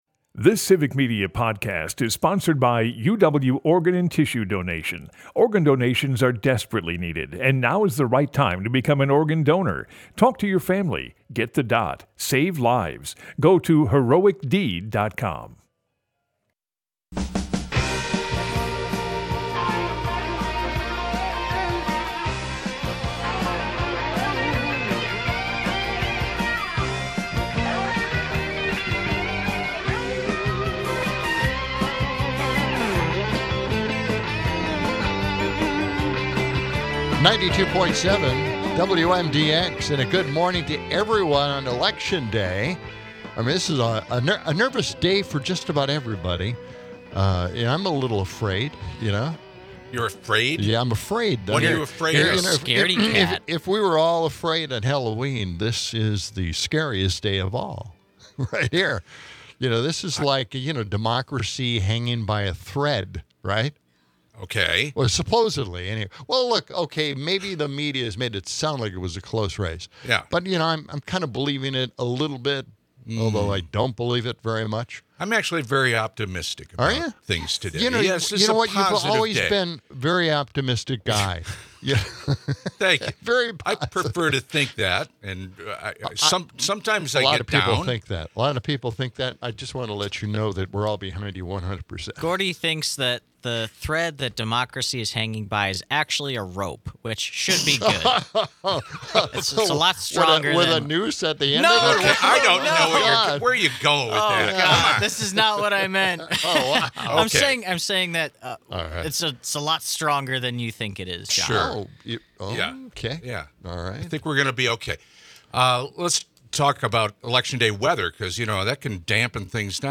Broadcasts live 6 - 8am weekdays in Madison.
Wrapping up hour one, the show gets a phone call from the Avengers (!) who are voting for Harris.